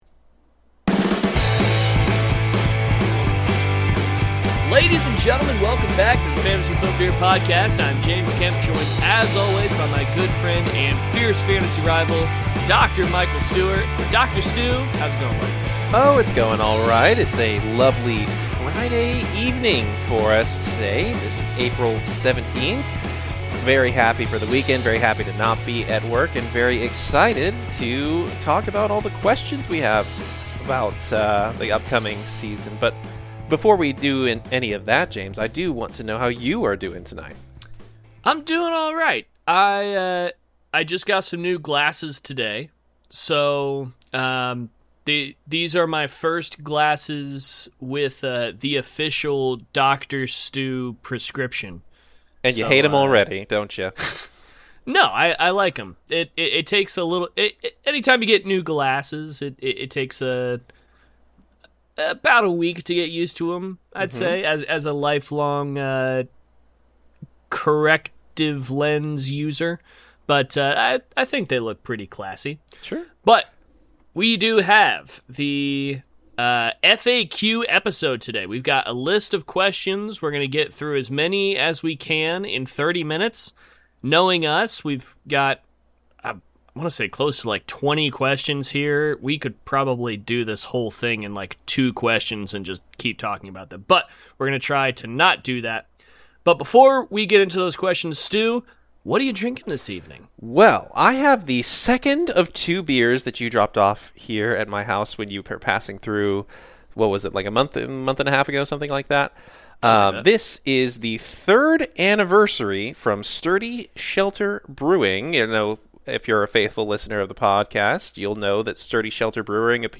Wherein the gents take listener submitted questions and answer them live!